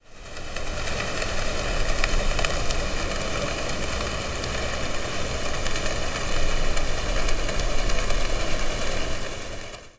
Barcos
El ruido de los motores se parece mucho al del motor de un auto.
Sonido de un barco bajo el agua
SHIP.wav